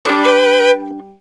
instrumentos / cordas
VIOLIN3.wav